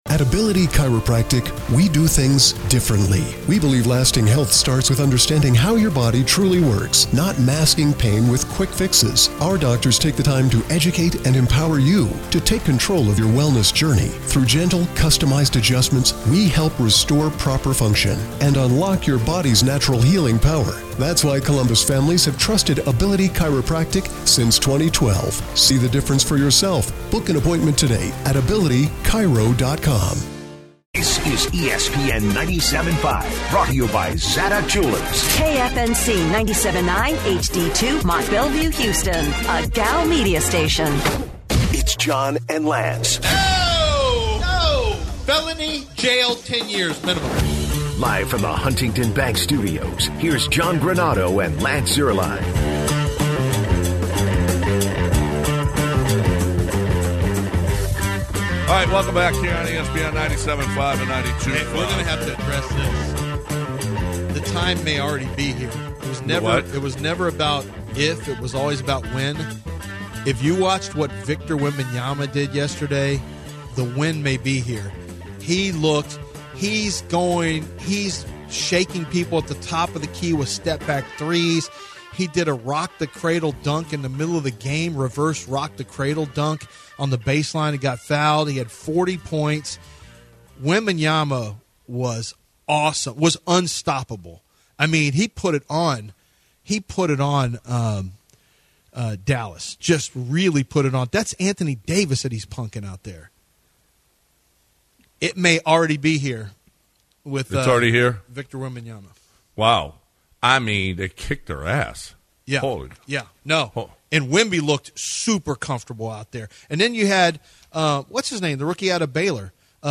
Callers talk Shoei Otani gambling, CJ Stroud, and Texans